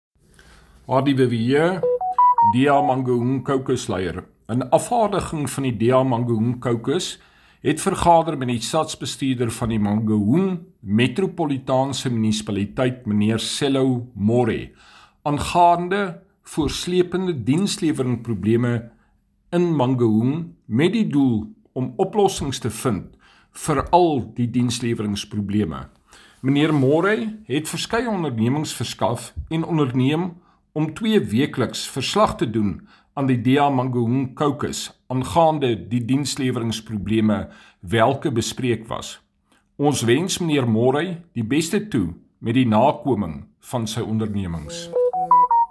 Afrikaans soundbites by Cllr Hardie Viviers and